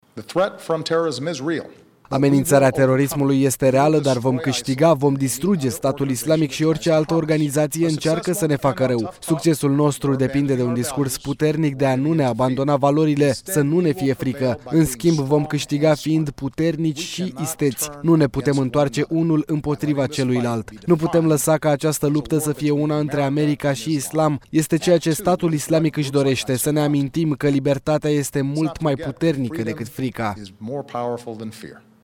Într-un discurs televizat către naţiunea americană pe tema amenințării teroriste, preşedintele Barack Obama a descris recentul atac armat din California, în care 14 persoane au fost ucise, drept un act de teroare, care a vizat oameni nevinovați.